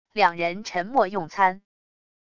两人沉默用餐wav音频